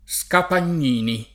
[ S kapan’n’ & ni ]